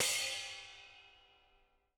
R_B Splash A 01 - Room.wav